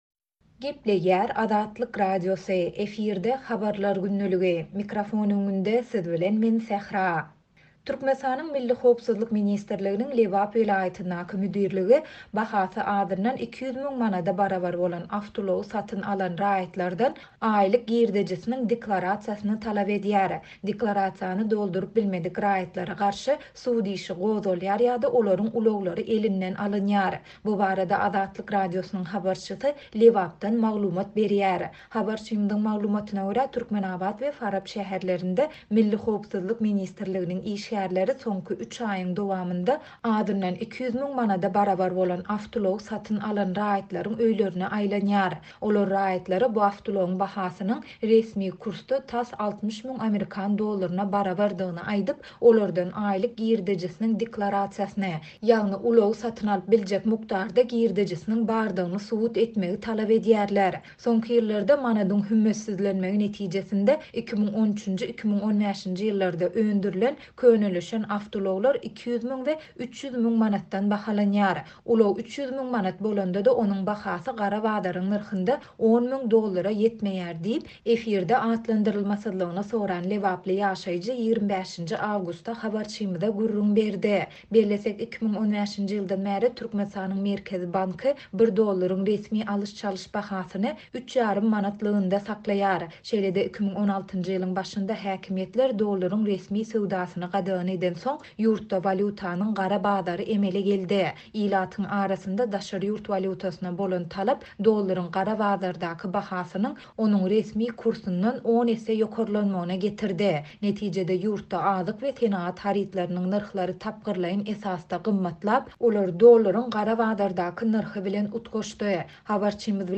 Deklarasiýany dolduryp bilmedik raýatlara garşy sud işi gozgalýar ýa-da olaryň ulaglary elinden alynýar. Bu barada Azatlyk Radiosynyň habarçysy Lebapdan maglumat berýär.